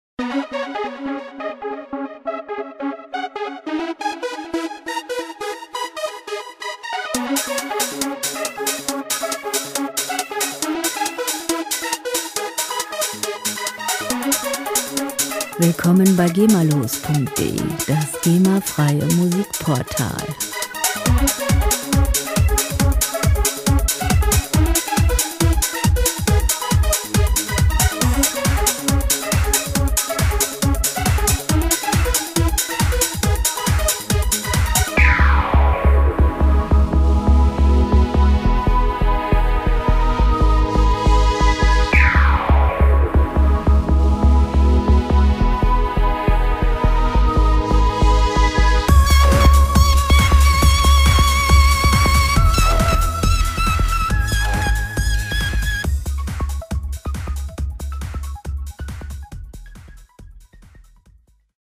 Musikstil: Trance
Tempo: 138 bpm
Tonart: Cis-Moll
Charakter: dynamisch, ryhthmisch
Instrumentierung: Synth, DrumLoop